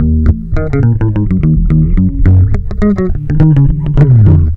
RI BASS 2 -R.wav